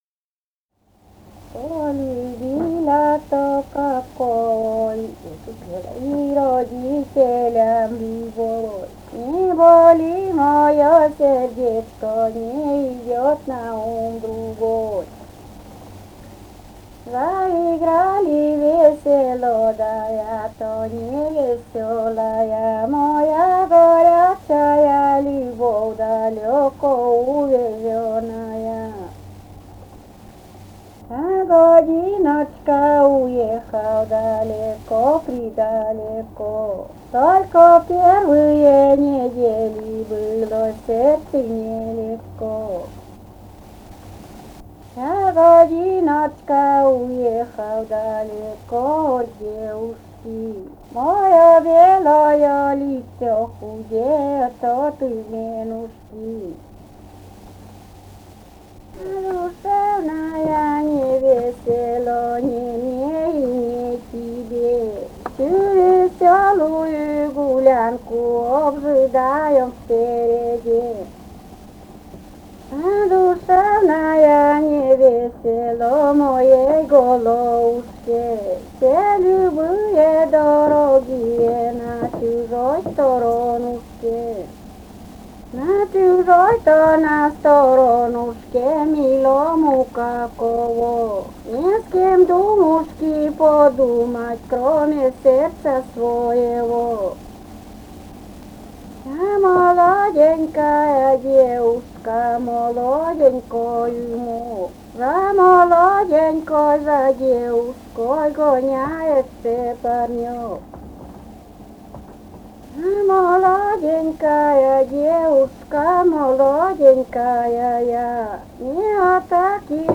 «Полюбила-то какого» (частушки).